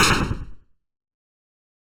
Explosion Small.wav